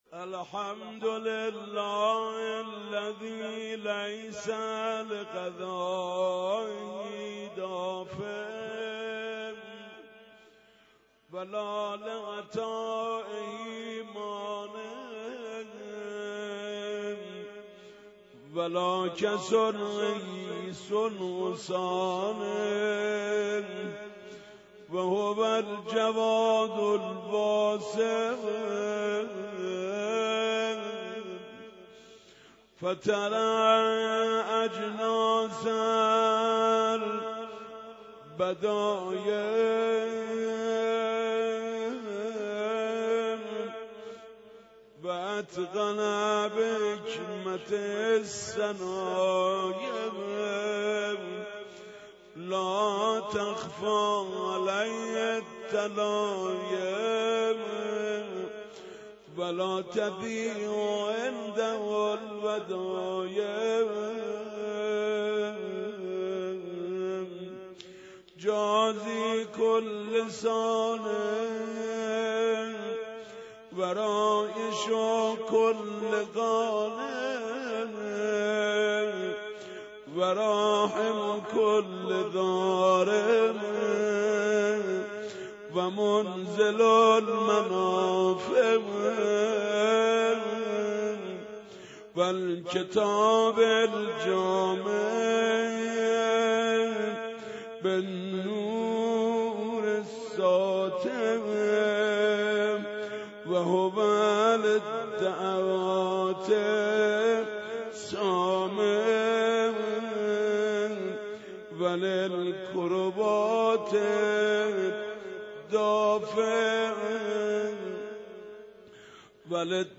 متن کامل دعای عرفه به همراه ترجمه + با نوای حاج محمود کریمی